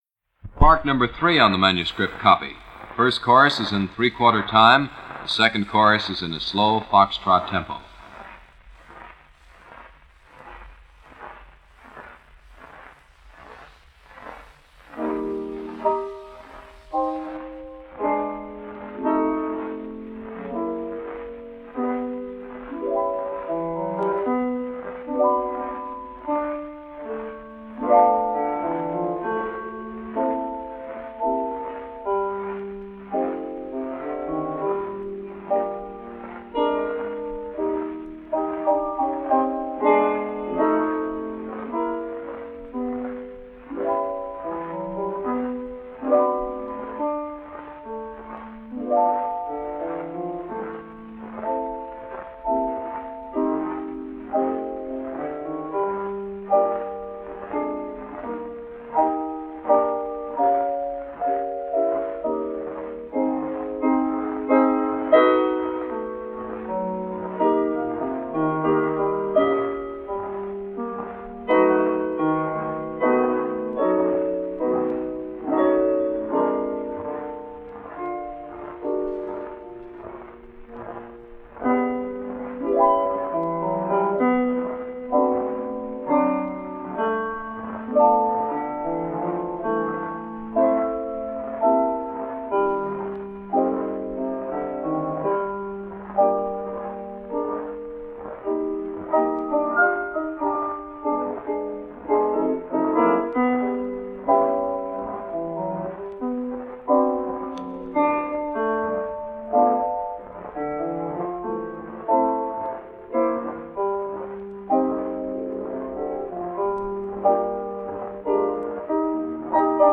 Original Origins unfinished melodies - Solo studio melodies Works in progress from the original manuscripts.  (circa mid 1930s)
1st chorus 3/4 time - 2nd slow fox trot tempo   KinnearKreations Audio Productions Melody #4